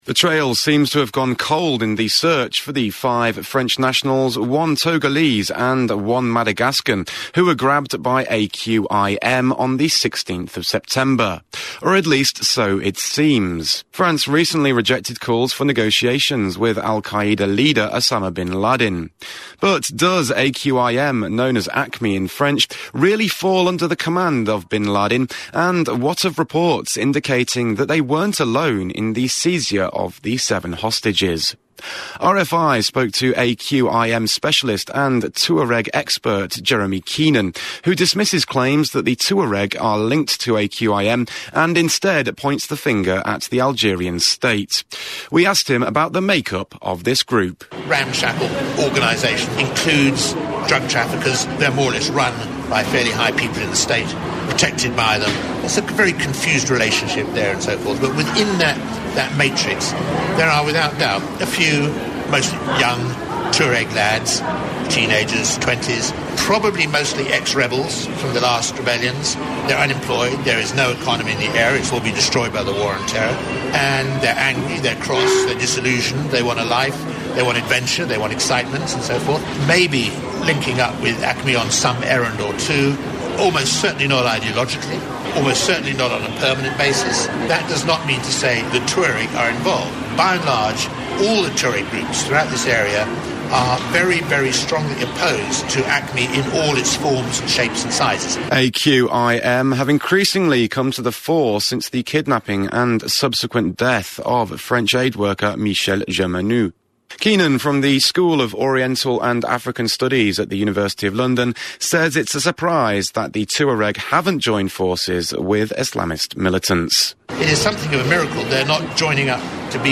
Radio Report